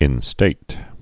(ĭn-stāt)